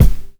DJP_KICK_ (30).wav